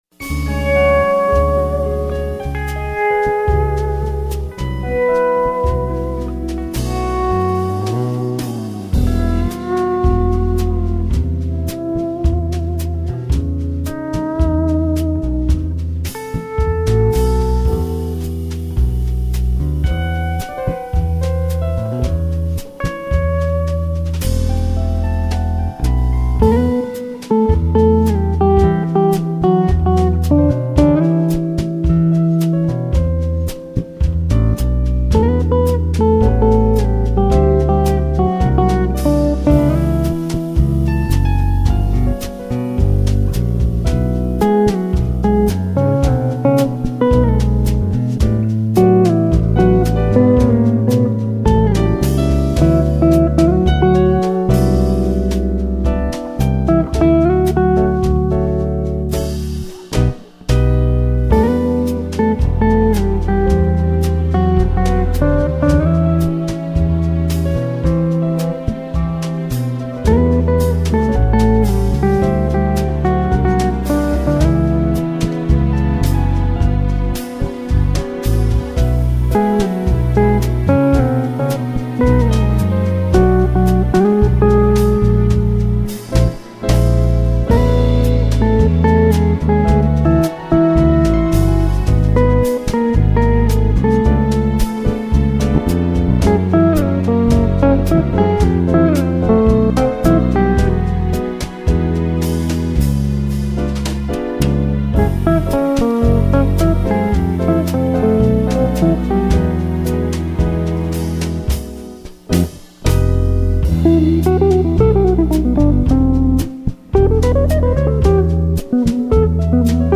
1308   06:22:00   Faixa:     Jazz